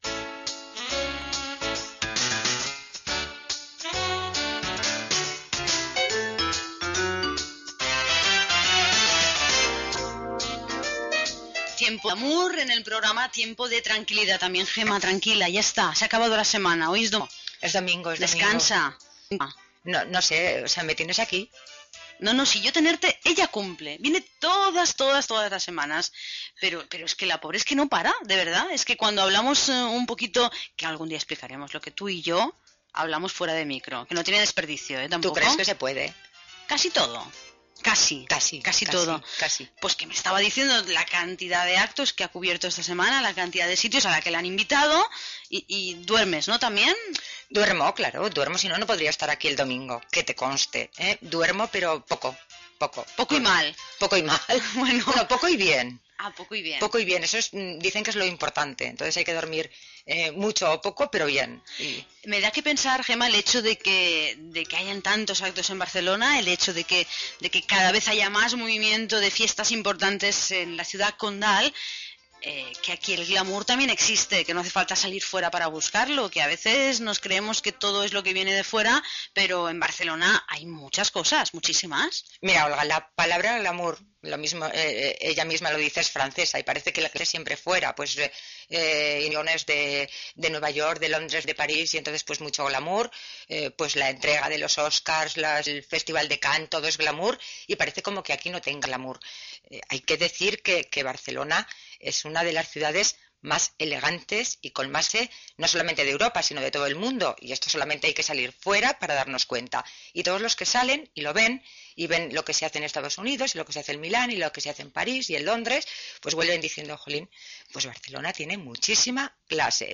Diàleg sobre el "glamour" de Barcelona, crònica de la inauguració de l'Hotel Casa Fuster, a Barcelona, i història de l'edifici modernista Gènere radiofònic Entreteniment